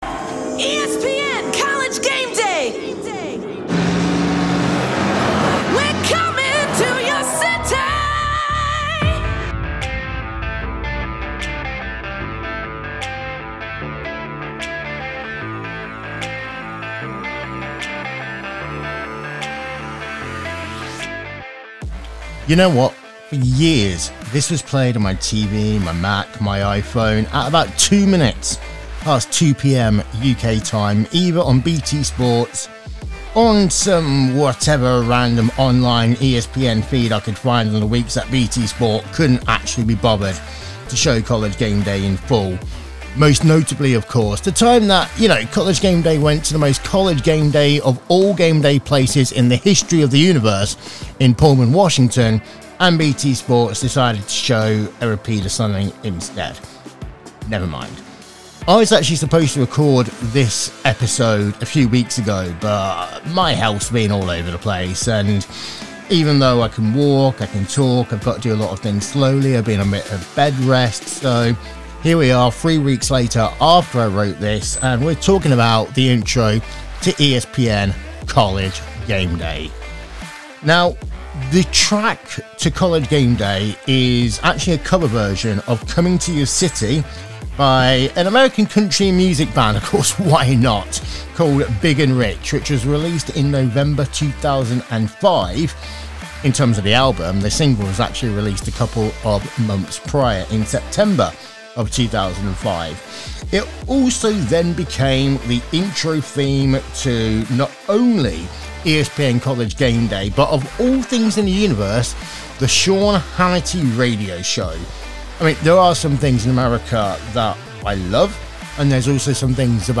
NOTE: I really apologise both for the lateness of this episode, and the nasal toning.